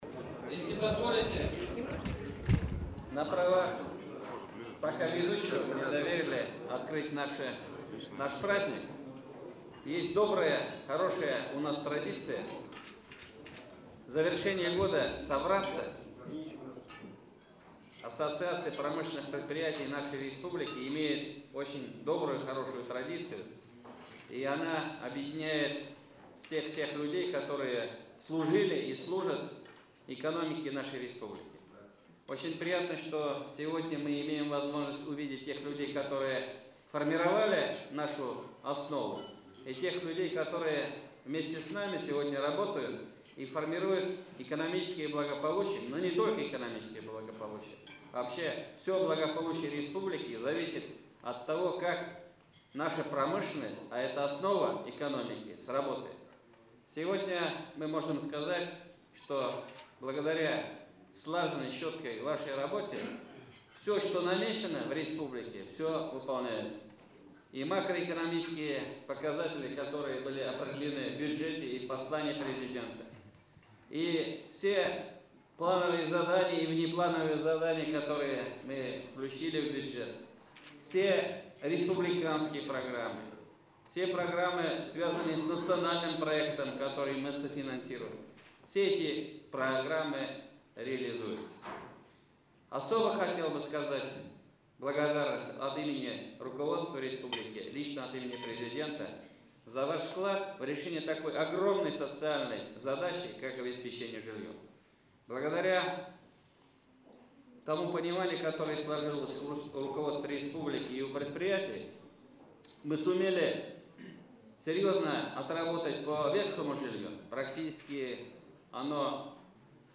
Аудиорепортаж
Встреча Премьер-министра Республики Татарстан Р.Н. Минниханова с руководителями промышленных предприятий, входящих в Ассоциацию предприятий и предпринимателей Республики Татарстан